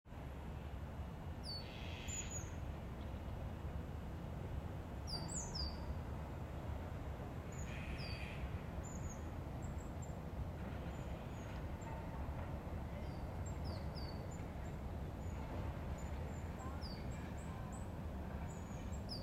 새소리.m4a
어느 날 찬 공기와 함께 창문에서 밀려온 지저귐이 온몸의 세포 하나하나를 깨웠다. 옆에서 까마귀가 울어도, 저 멀리서 자동차 소리가 방해해도 그 소리에만 집중됐다.